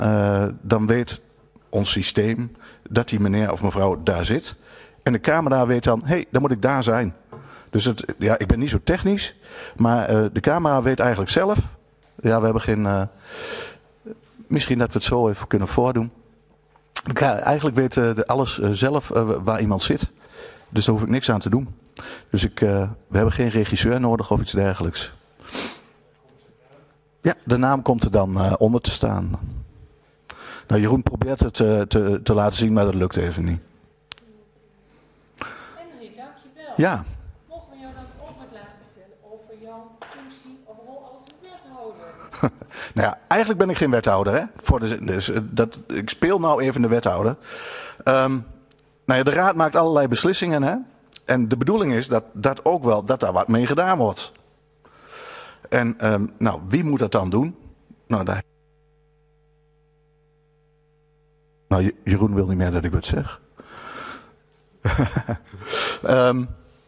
Raadzaal